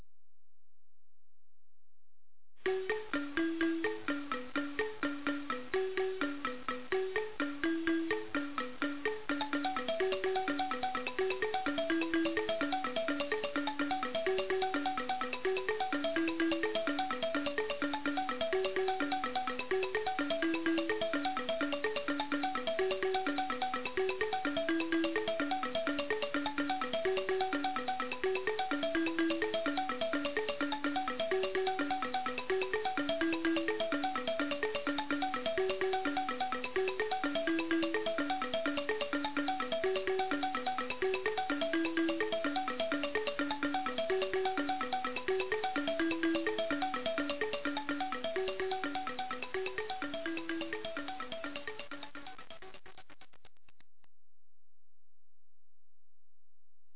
AMADINDA - das Xylophon aus Buganda
Die folgenden Klangbeispiele (Computersimulationen) zeigen am Lied Ssematimba ne Kikwabanga (Ssematimba und Kikwabanga), wodurch die Wahrnehmung der zweitönigen (und der dreitönigen) Melodie unterstützt oder gestört werden kann.